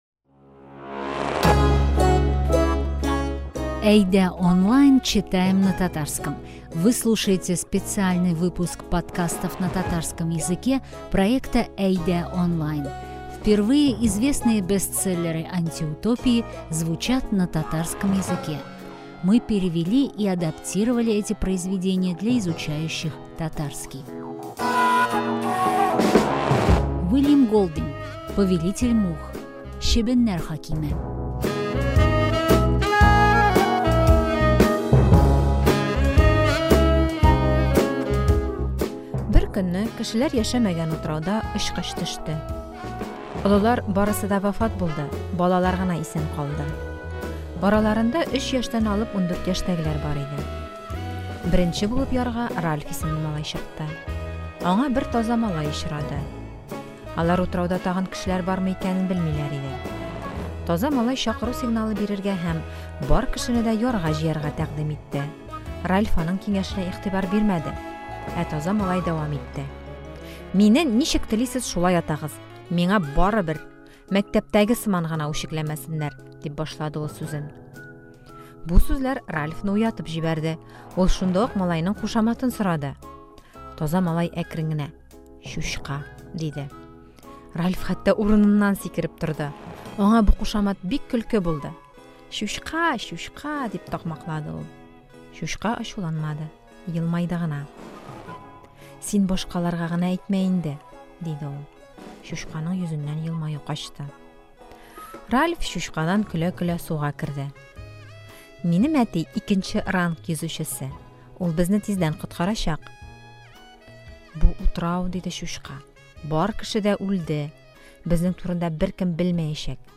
На примере группы детей автор показывает, как может развиваться диктатура в любом обществе. Мы подготовили пересказ этого произведения на татарском, с аудио и переводом ключевых фраз.